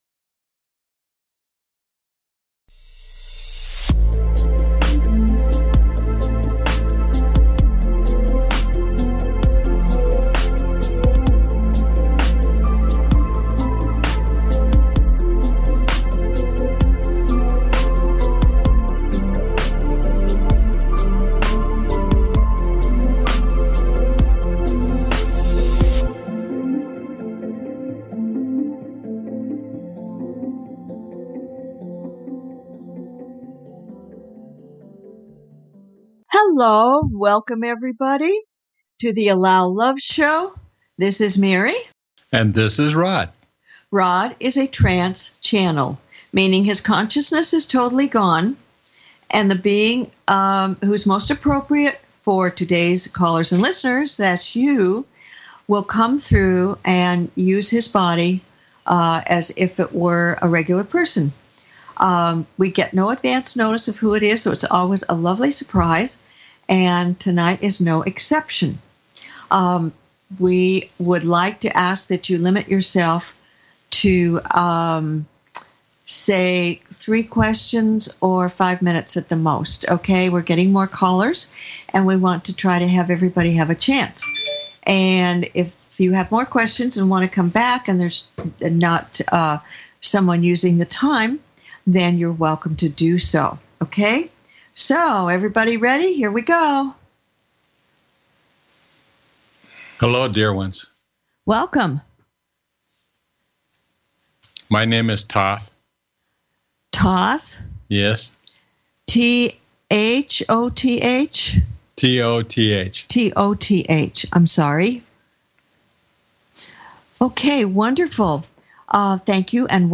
Talk Show Episode, Audio Podcast, The Allow Love Show and with Toth (Thoth), ancient Egyptian god on , show guests , about Toth,Thoth,ancient god,Egyptian god, categorized as Paranormal,Ghosts,Philosophy,Access Consciousness,Medium & Channeling